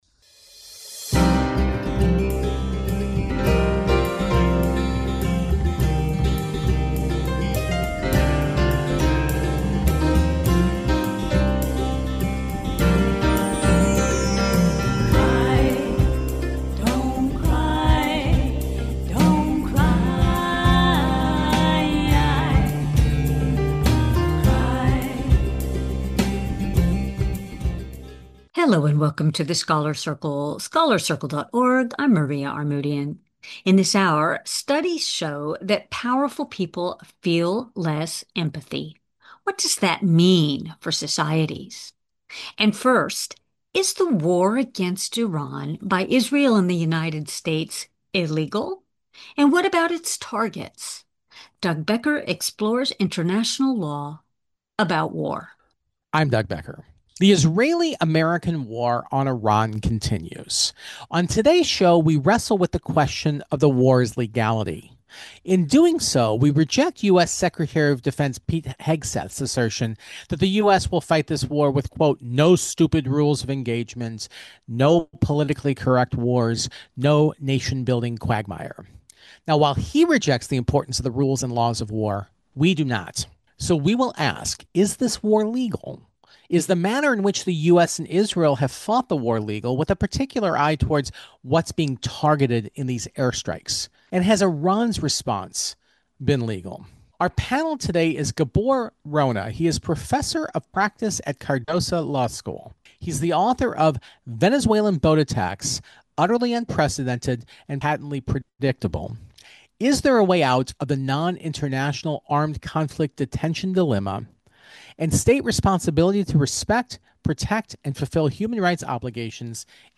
On today’s panel we wrestle with the question of the war’s legality.